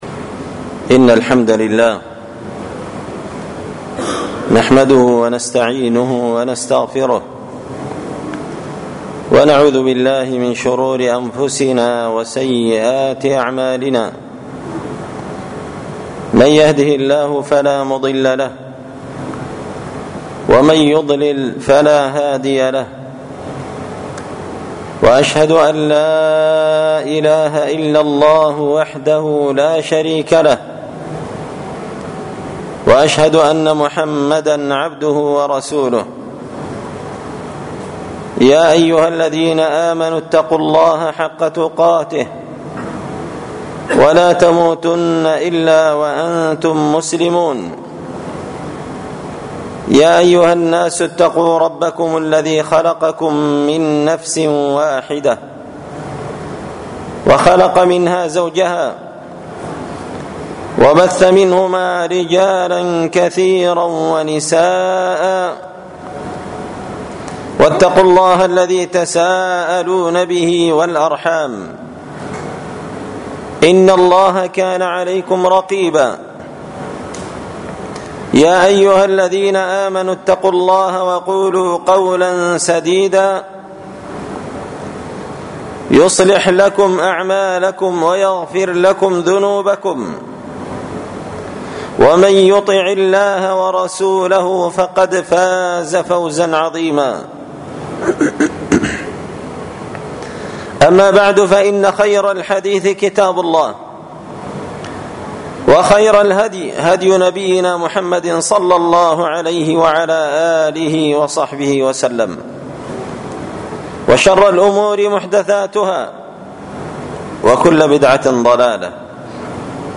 ألقيت هذه الخطبة في مسجد الرياضضبوت-المهرة-اليمن تحميل…